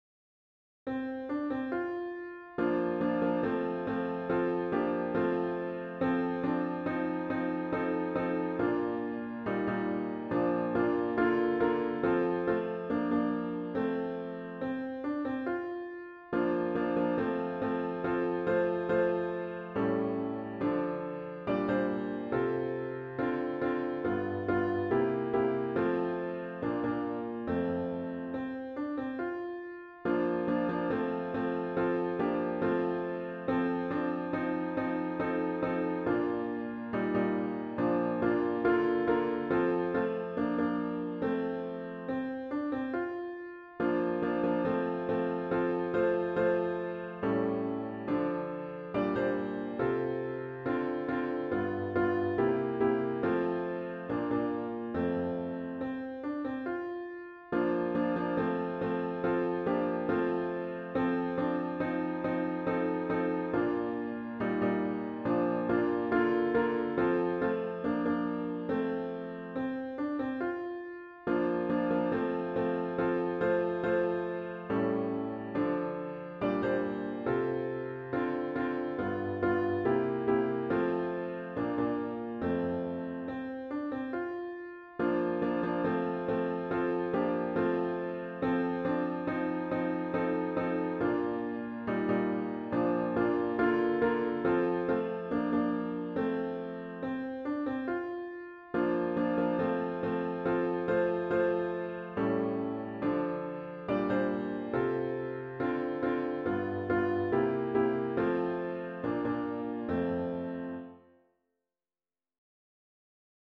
HYMN   “I’m Gonna Live So God Can Use Me”